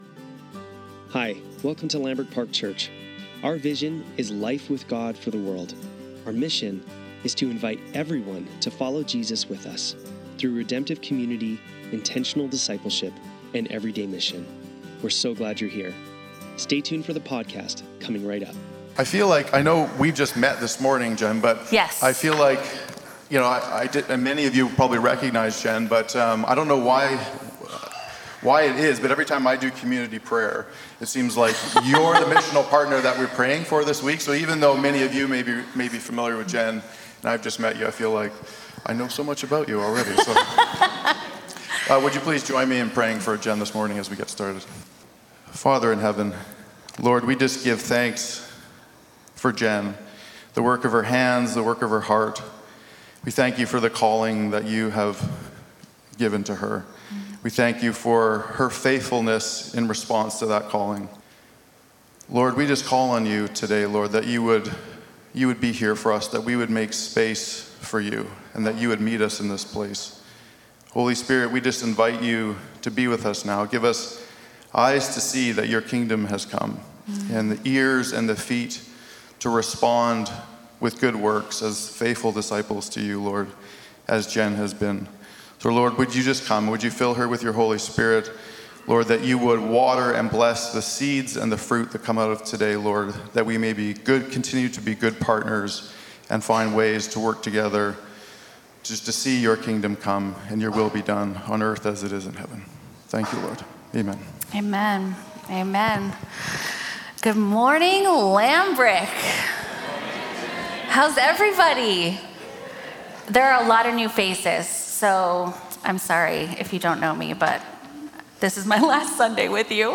Sermons | Lambrick Park Church
Sunday Service - June 8, 2025